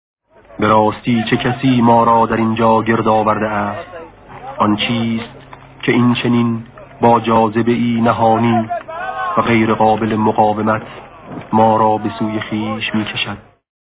صدای شهید آوینی ؛ به راستی چه کسی ما را در اینجا گرد آورده است؟